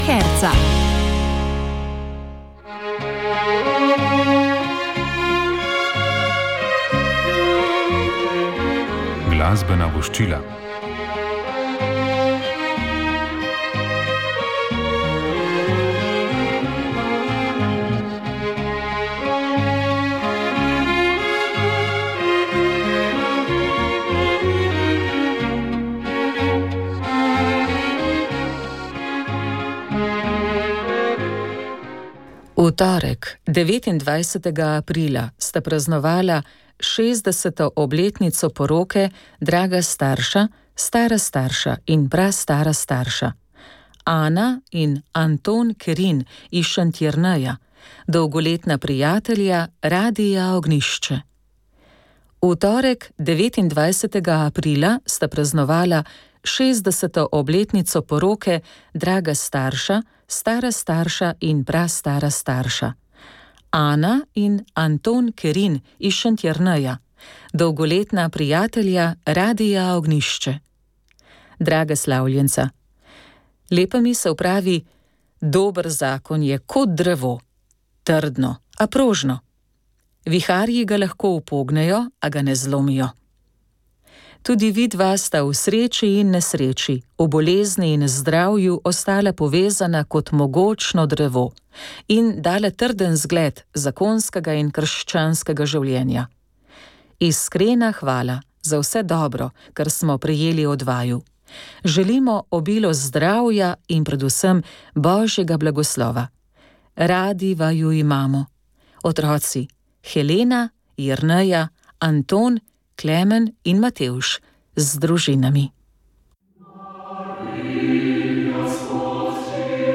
Vremenoslovci in klimatologi ob tem svarijo, da bodo tovrstni naravni pojavi kot posledica podnebnih sprememb vse pogostejši in silovitejši. V studiu smo gostili poveljnika Civilne zaščite za severno primorsko mag. Sama Kosmača.